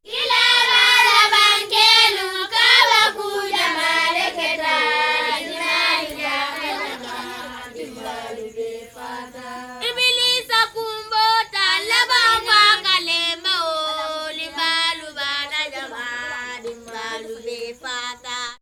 VOC 04.AIF.wav